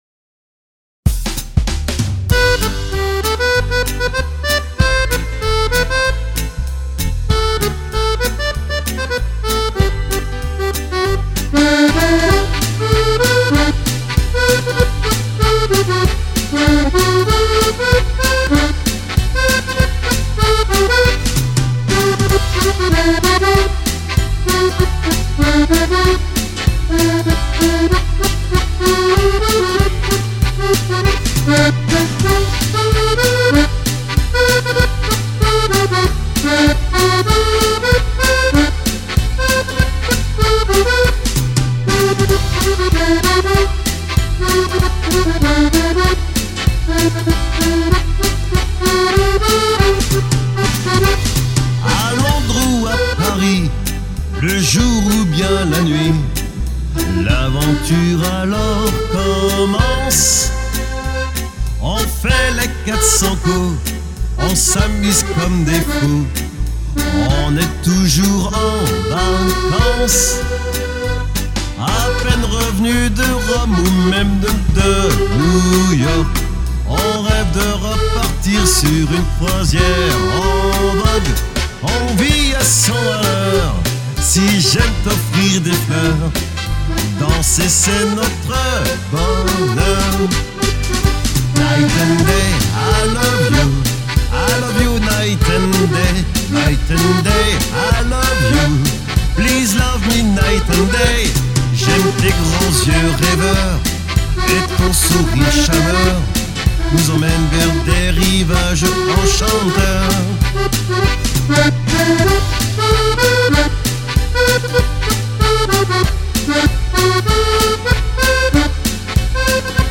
(Quick step)